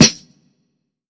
West Snare.wav